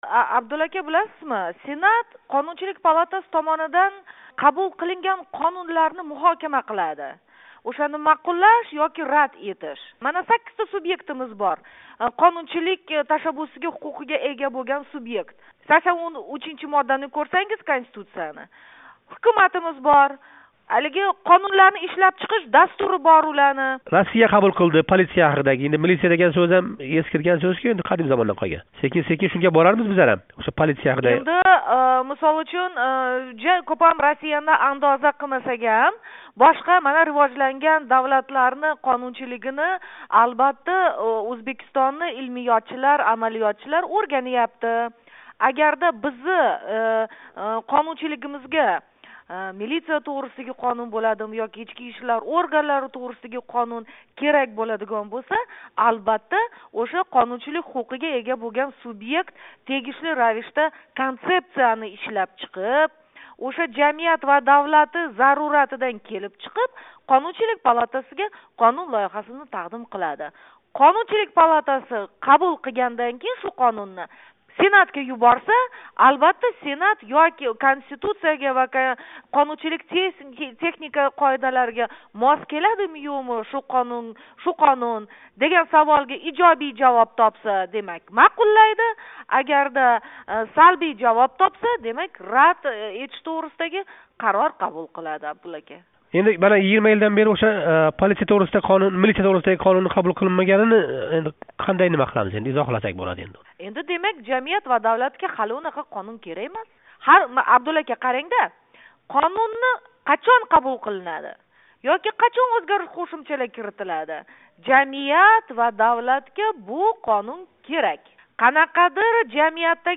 Ўзбекистон Сенати Қонунчилик ва суд-ҳуқуқ қўмитаси раиси Светлана Ортиқова билан суҳбат.